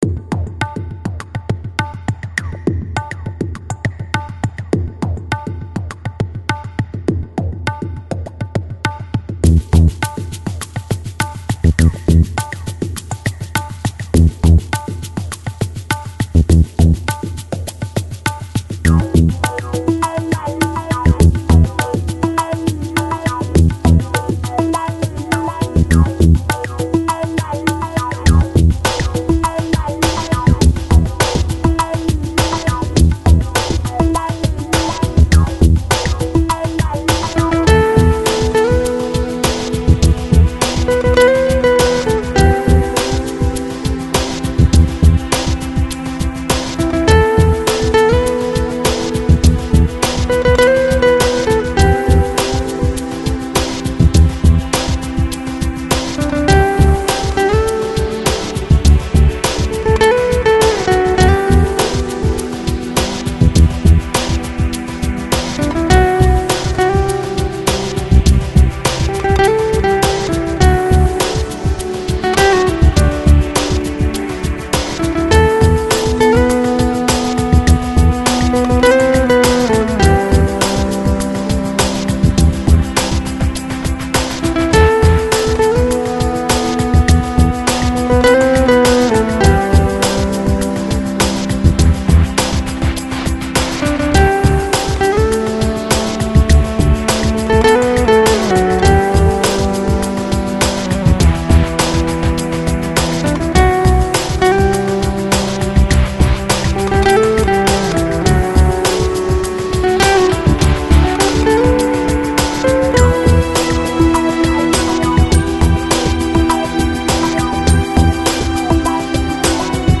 Жанр: Lounge, Chill Out, Downtempo